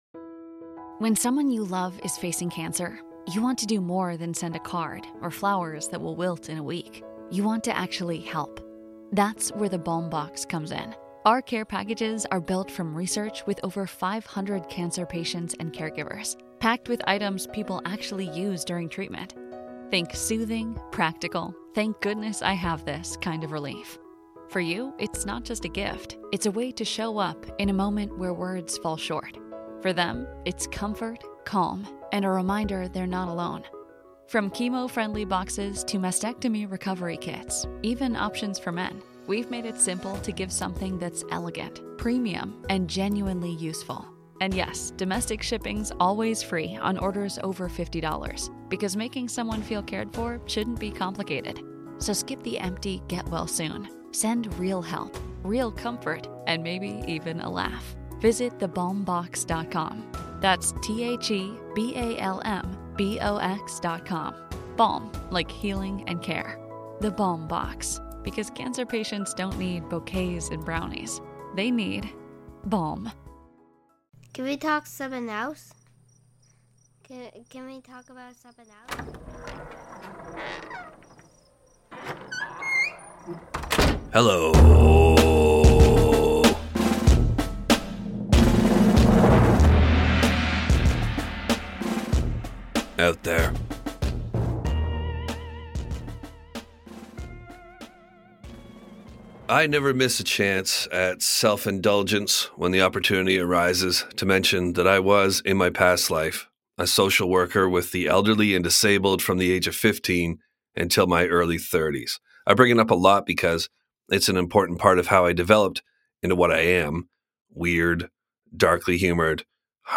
1 Leadership & Career Pivots: A Conversation